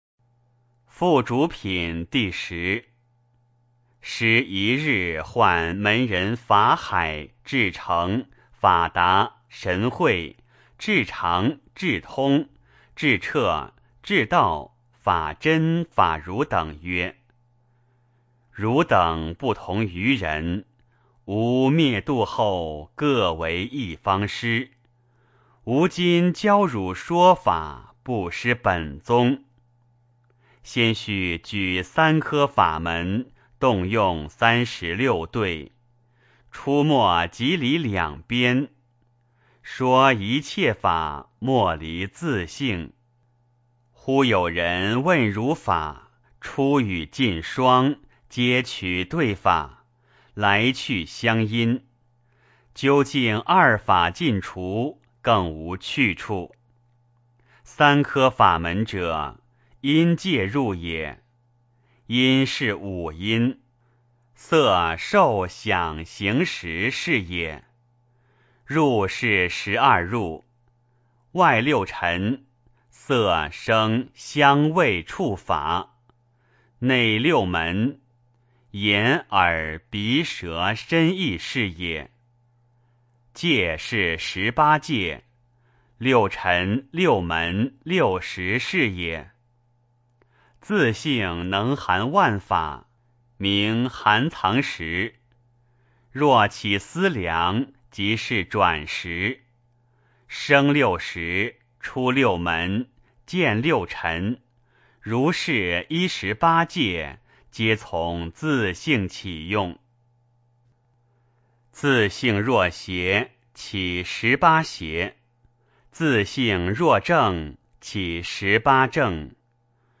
六祖坛经-10付嘱品（念诵） 诵经 六祖坛经-10付嘱品（念诵）--未知 点我： 标签: 佛音 诵经 佛教音乐 返回列表 上一篇： 六祖坛经-07机缘品（念诵） 下一篇： 金刚经（诵念） 相关文章 信心一炷香--觉慧法音合唱团 信心一炷香--觉慧法音合唱团...